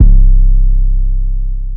808 (Never Stop).wav